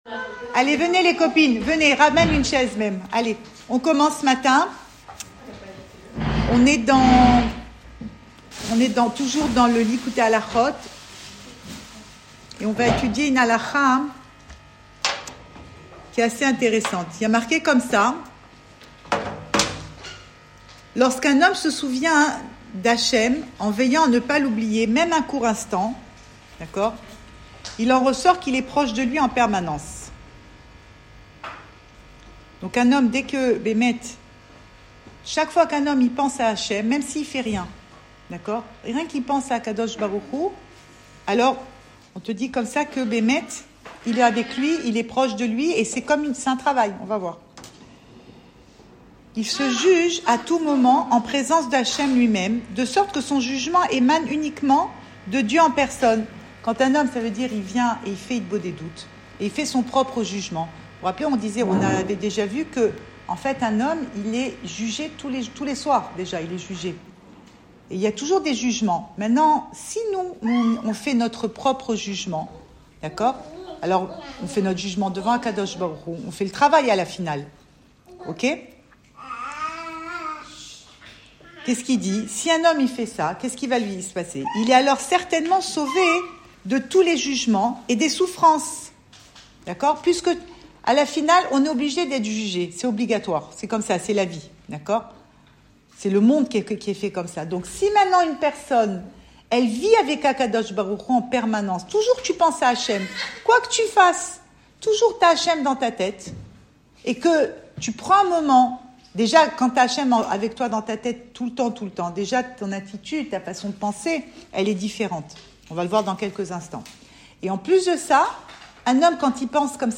Cours audio Emouna Le coin des femmes Le fil de l'info Pensée Breslev - 14 mai 2025 16 mai 2025 L’auto-jugement. Enregistré à Tel Aviv